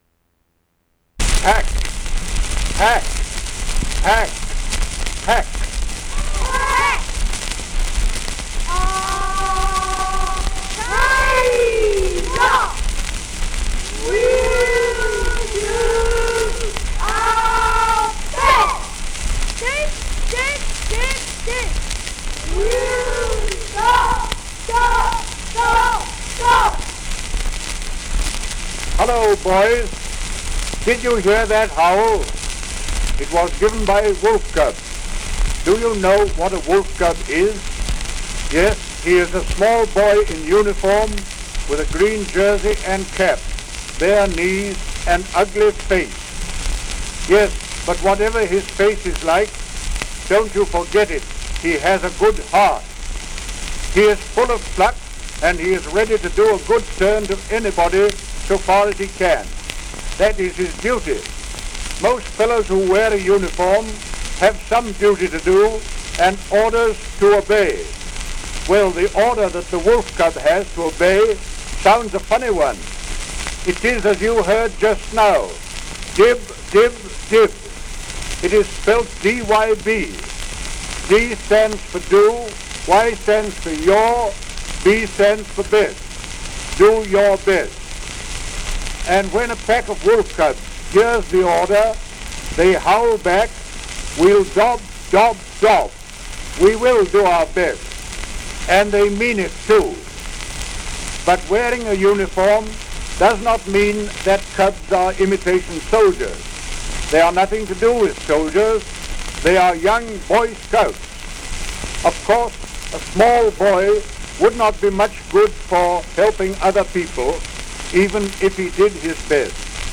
Lord Baden-Powell, founder and first Chief Scout of The Boy Scouts Association, gives a speech for and about the Wolf Cub Boy Scouts in London. Begins with the Wolf Cub Grand Howl.